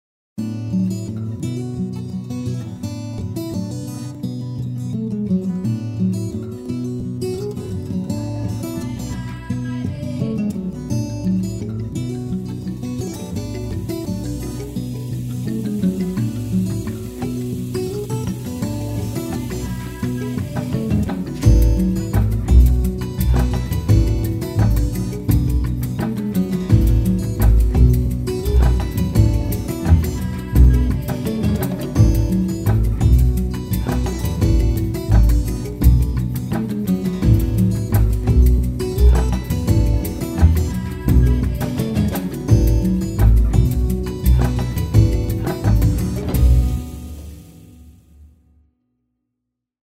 aerien - nostalgique - folk - melancolie - voix